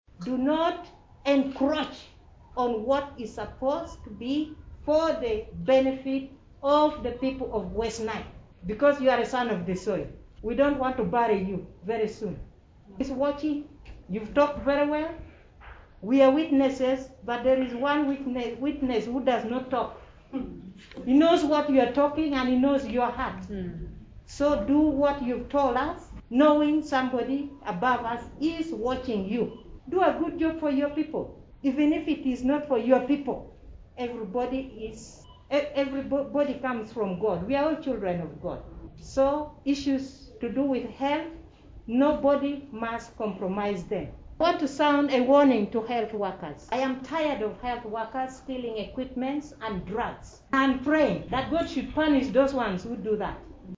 Resident City Commissioner of Arua city, Alice Akello appealed to the contractor to do quality work and also cautioned the health workers against the theft of hospital equipments.